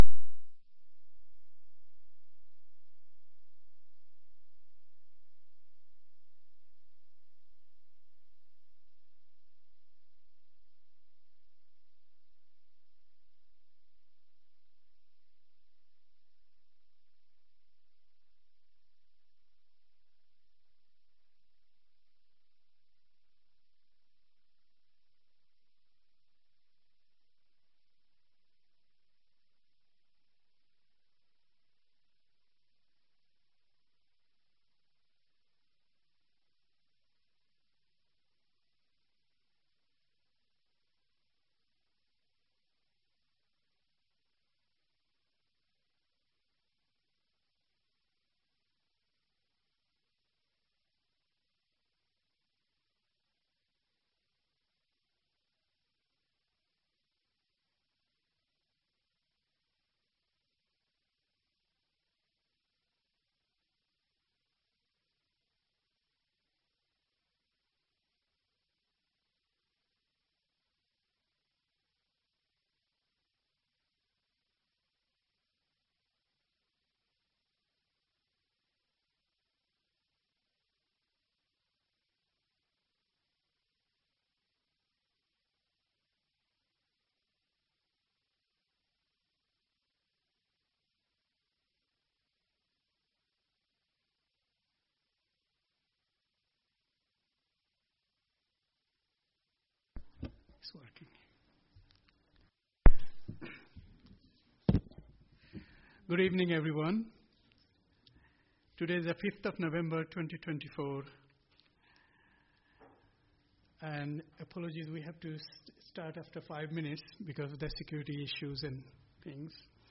Committee Licensing Committee Meeting Date 05-11-24 Start Time 5.30pm End Time 7.10pm Meeting Venue Coltman VC Room, Town Hall, Burton upon Trent Please be aware that not all Council meetings are live streamed.
Meeting Recording 241105.mp3 ( MP3 , 22.34MB )